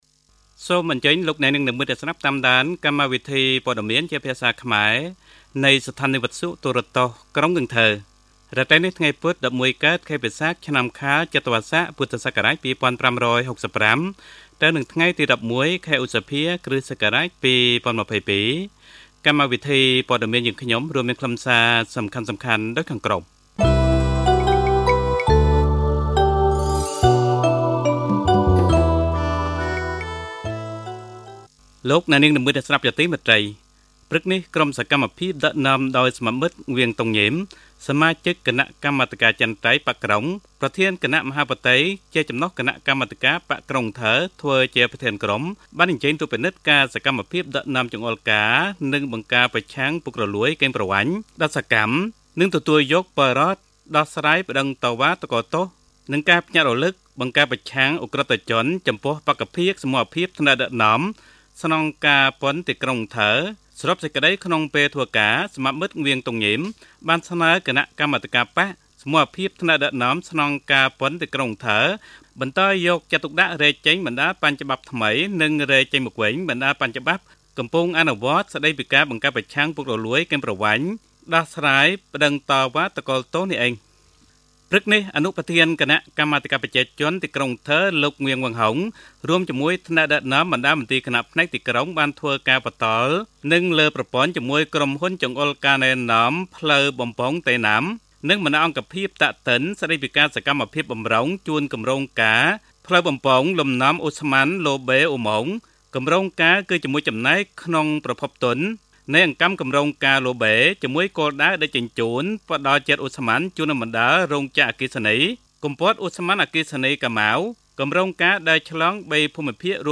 Bản tin tiếng Khmer tối 11/5/2022